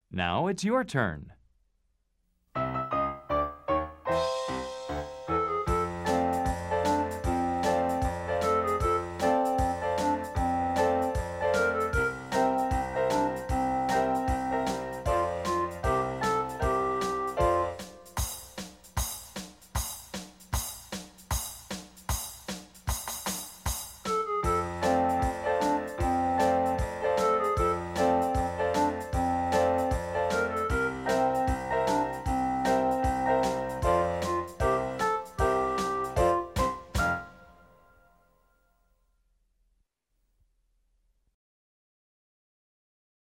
牛津少儿英语 let's chant 48 - The Happy Birthday Song (music only) - 41 s. 听力文件下载—在线英语听力室